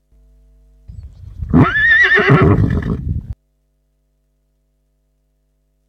Horse Whinny 10 Sound Effect Free Download
Horse Whinny 10